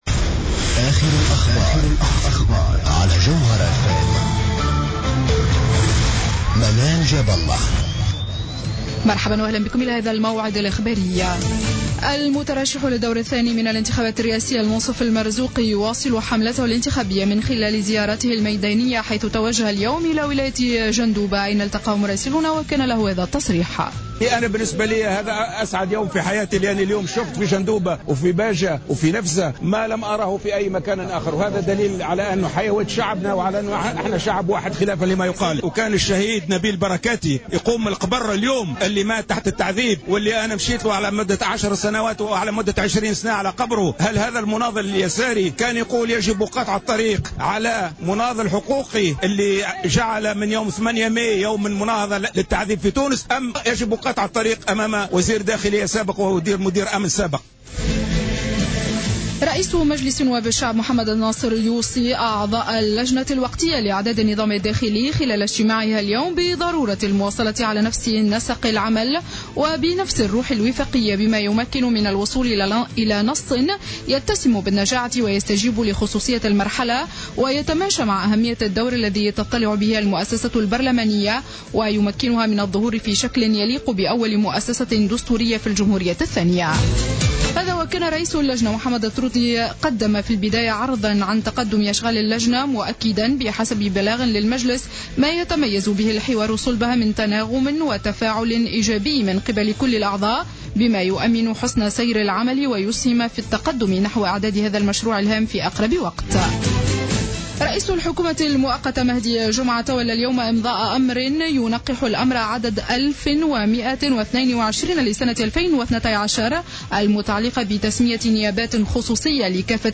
نشرة أخبار السابعة مساء ليوم الجمعة 12-12-14